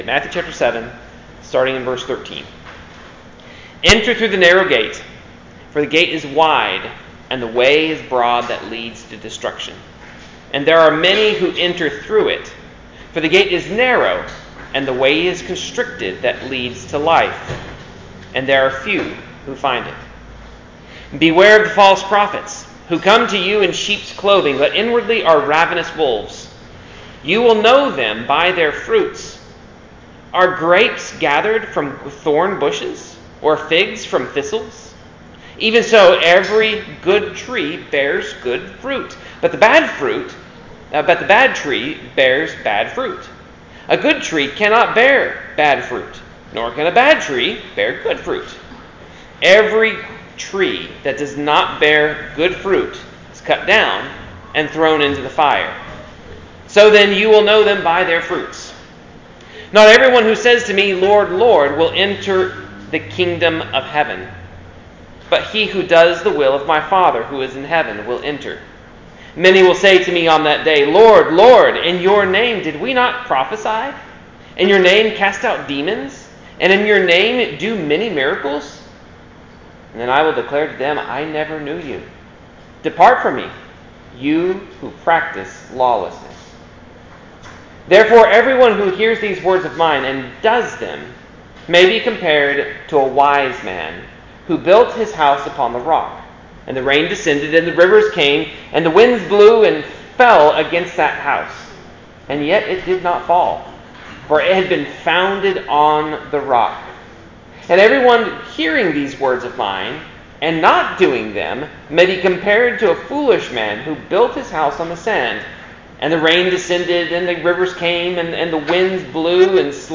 Location / Localidad: Jersey Central Series: Sermon on the Mount Passage: Matthew 7.13-29 Language / Lenguaje: English / Inglés « Rev. 13-14a The Dragon Made War with the Saints